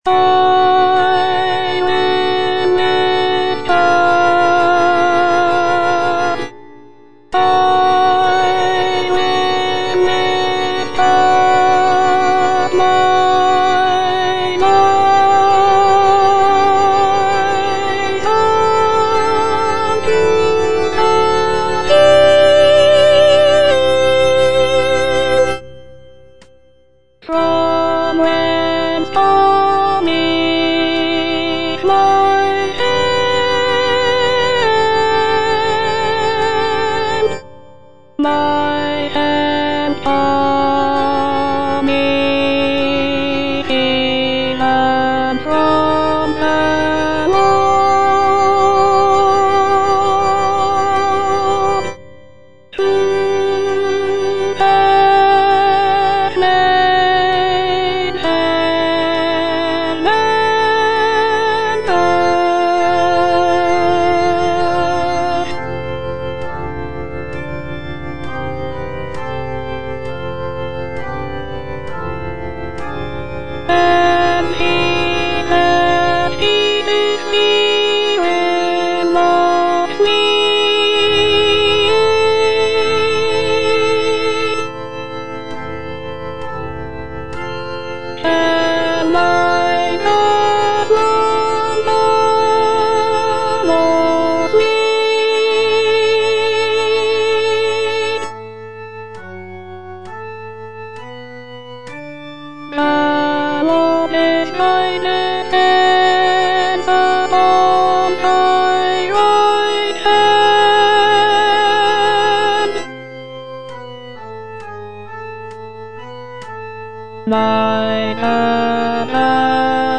Alto I (Voice with metronome)